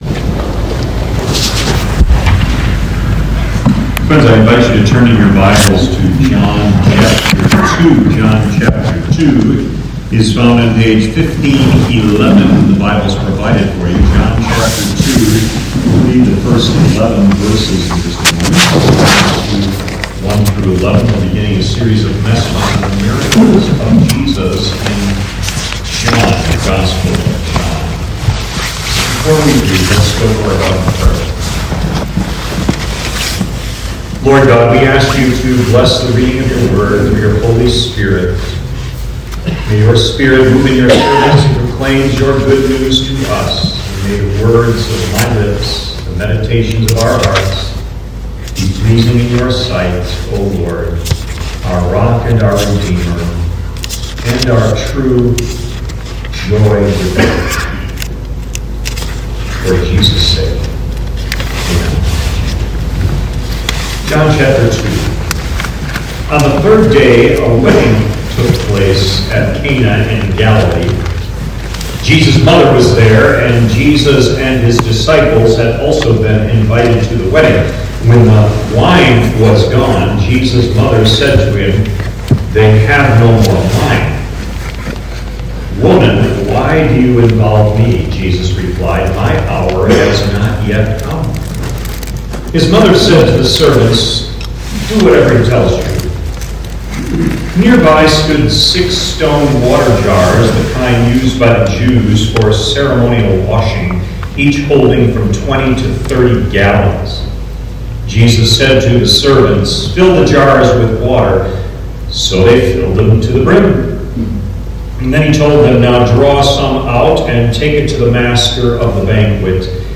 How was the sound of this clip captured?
Note: Due to technican difficulties, the audio recording is very muffled.